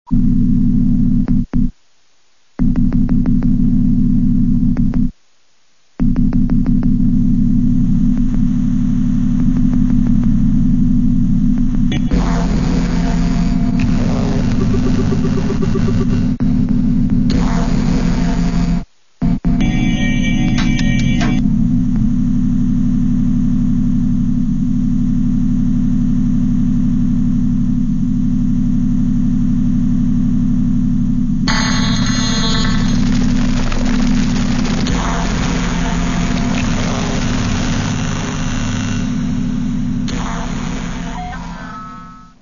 Electro-Acoustic Experiments